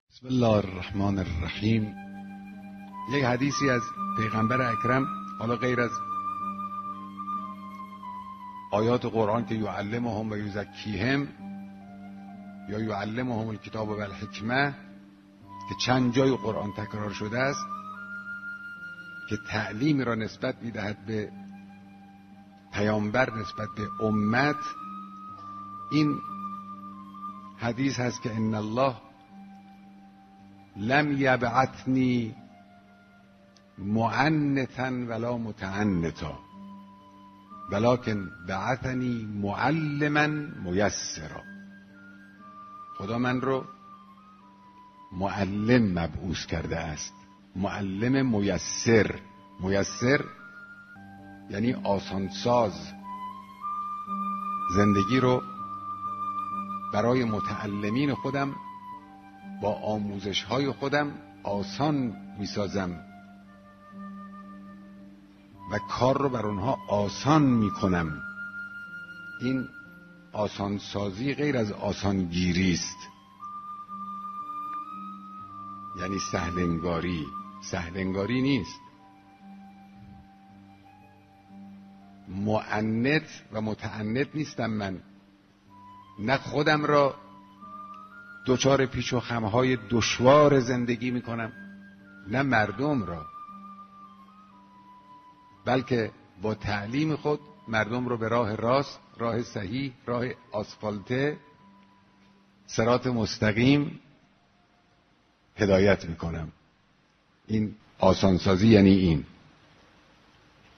• مقام معظم رهبری, صوت بیانات رهبر, پیامبر اکرم, رهبر انقلاب اسلامی